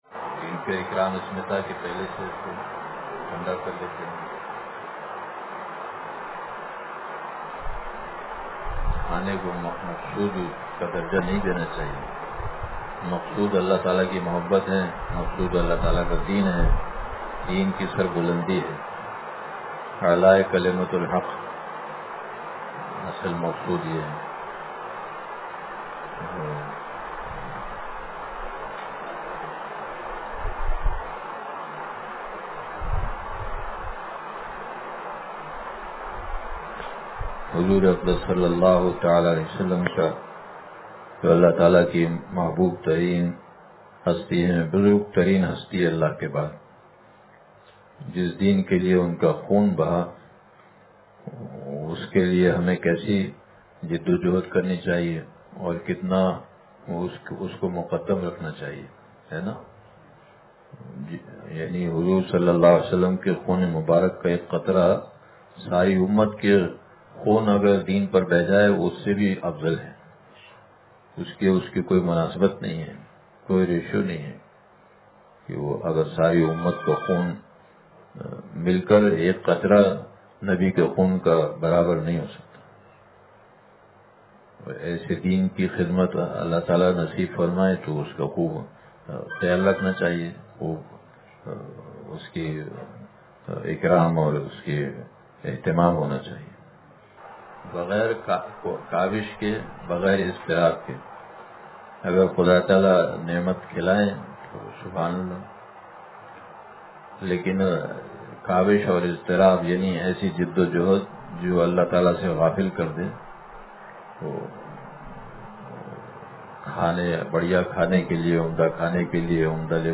زبانِ عشق – مجلس بروز جمعرات